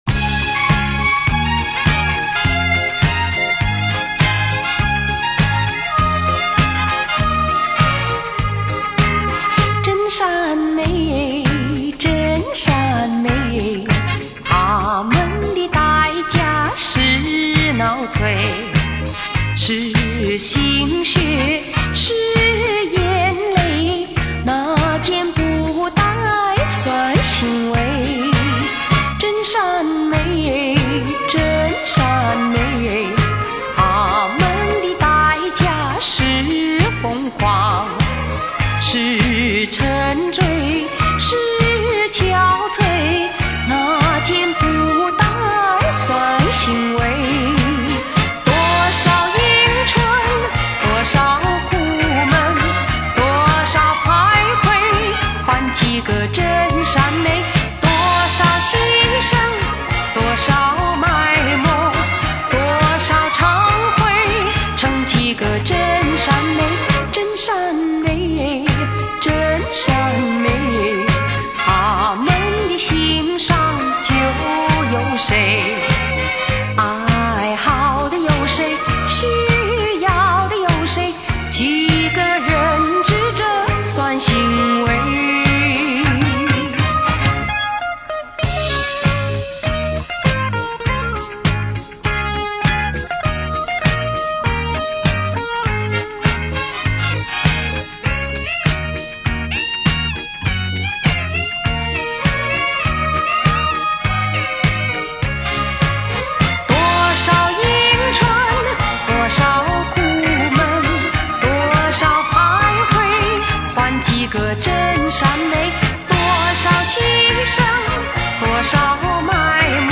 〖怀旧经典〗